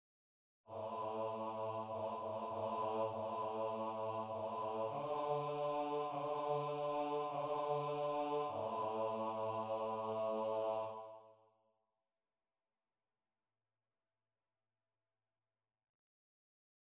Key written in: F# Major
Type: SATB
Learning tracks sung by